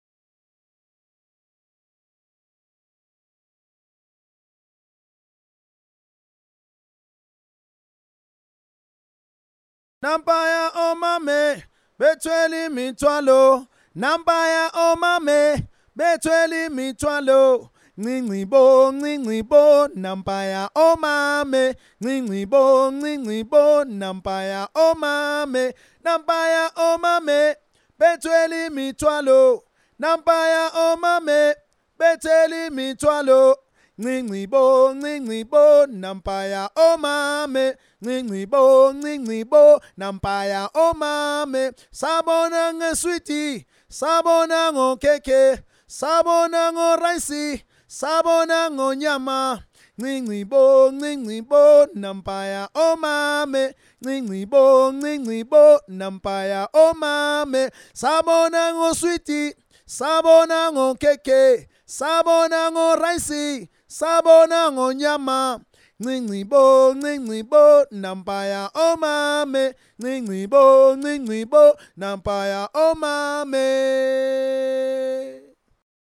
Enregistrement voix 1
musiqueprimnampayaomanevoix-1.mp3